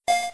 pokeball_land.wav